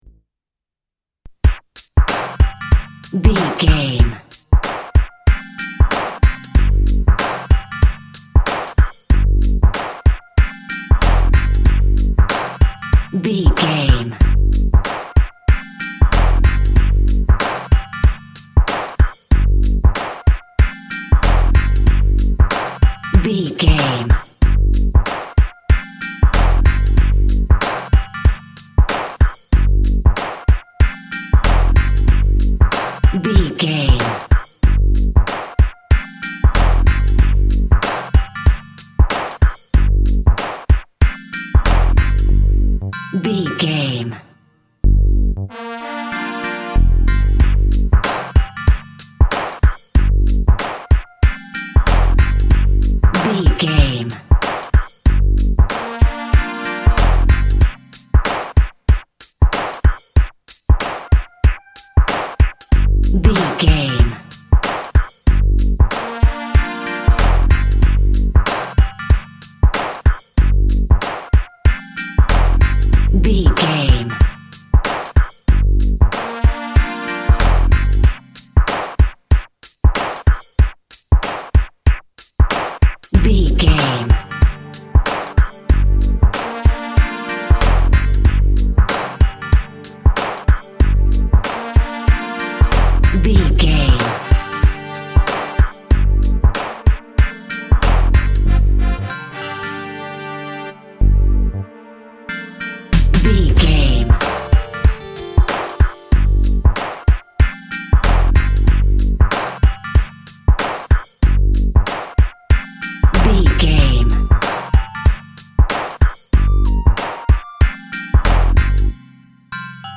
Rap Metal Music Cue.
Aeolian/Minor
hip hop
synth lead
synth bass
hip hop synths